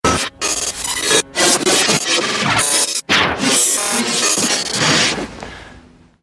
hip-hop-sound-fx_24635.mp3